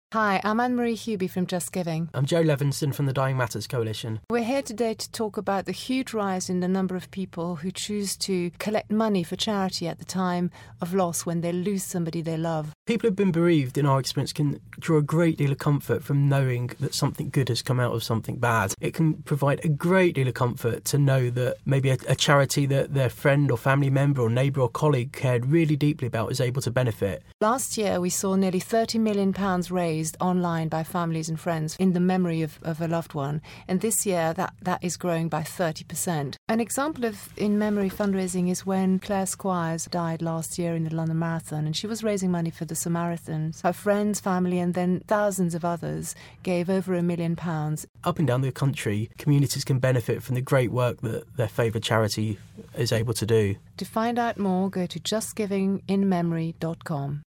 visited our On Broadcast studio to discuss the new research by Just Giving that reveals more than £27 million was raised from donations 'in-memory' of loved ones Online last year...